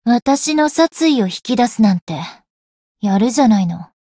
灵魂潮汐-星见亚砂-互动-不耐烦的反馈2.ogg